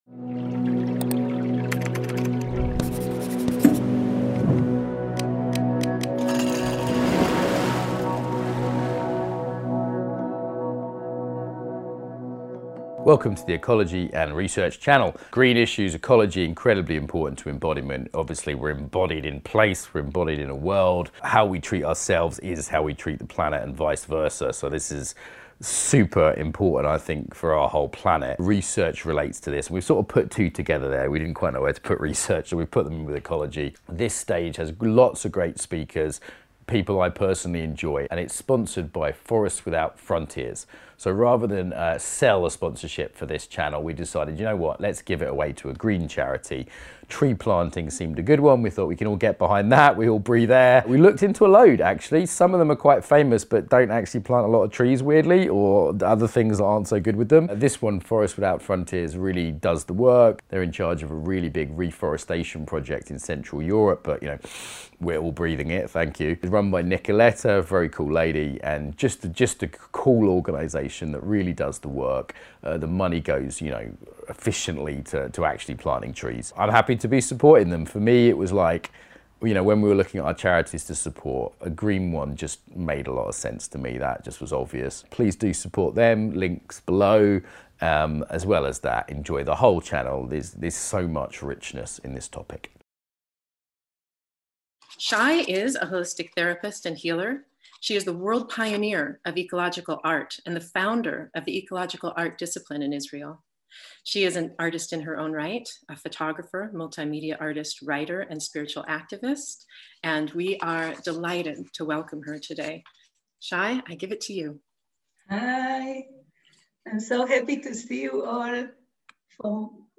Spacing Within / guided imagery.
Beginning or no understanding of topic, Open to all Movement not required Likely soothing How can we create more space for breathing and living in our body-mind- soul and reconnect to natural entities?
Download Notes (PDF) Download Audio Download Video Guided Practices Guided Meditation 7:5 save All Ecology & Research presentations proudly sponsored by Forest without frontiers .